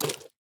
Minecraft Version Minecraft Version snapshot Latest Release | Latest Snapshot snapshot / assets / minecraft / sounds / mob / armadillo / eat2.ogg Compare With Compare With Latest Release | Latest Snapshot
eat2.ogg